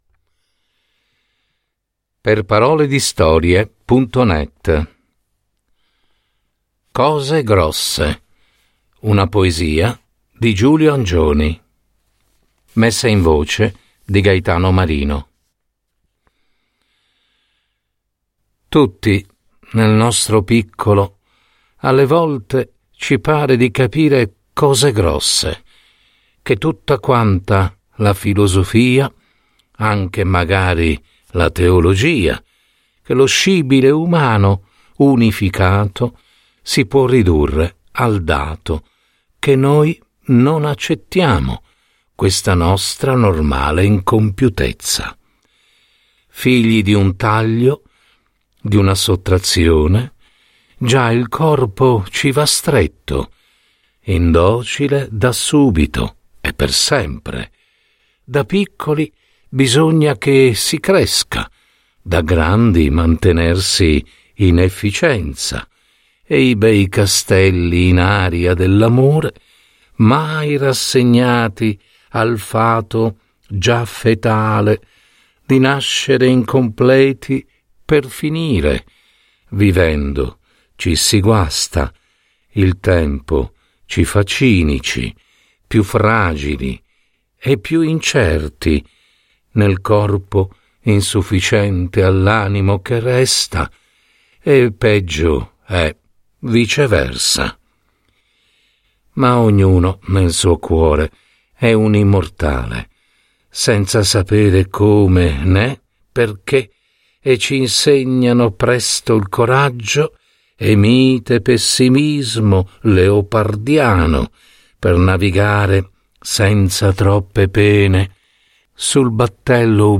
Cose grosse. Una poesia di Giulio Angioni